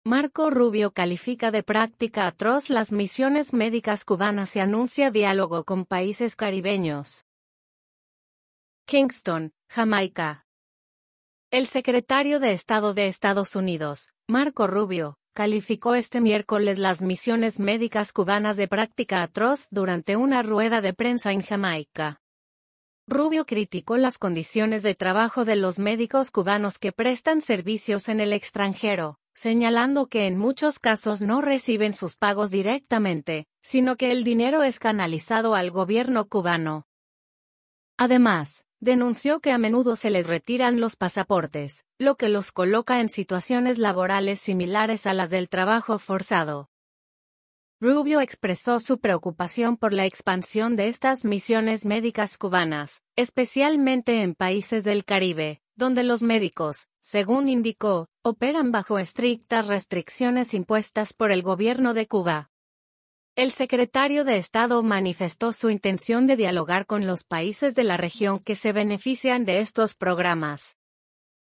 Kingston, Jamaica. – El secretario de Estado de Estados Unidos, Marco Rubio, calificó este miércoles las misiones médicas cubanas de “práctica atroz” durante una rueda de prensa en Jamaica.